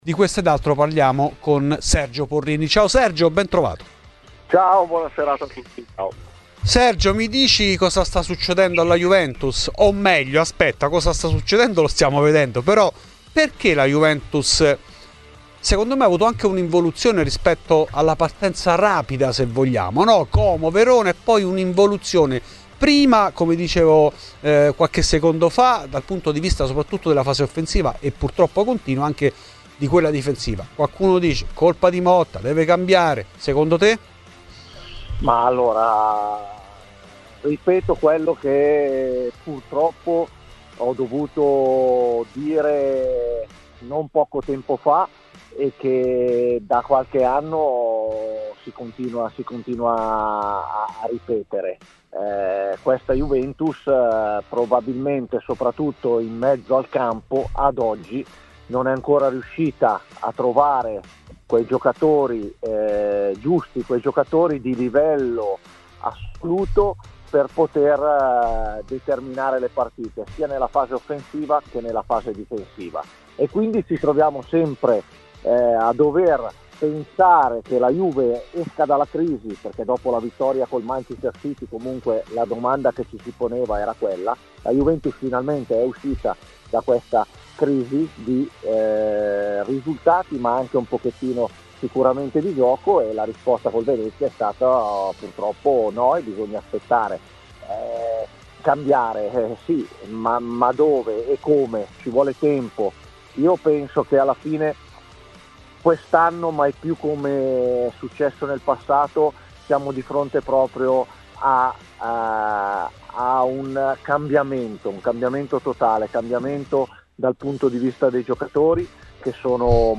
Sul tavolo della discussione resta anche il lavoro di Motta, rispetto comunque ad un progetto nuovo e il prossimo mercato, in particolare la scleta del difensore. Questo ed altro nell'intervento in ESCLUSIVA a Fuori di Juve di Sergio Porrini.